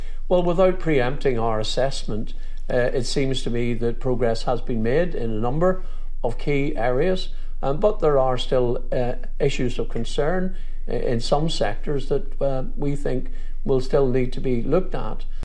DUP leader Jeffrey Donaldson says his party will consider the text of the deal to see whether they can support it…………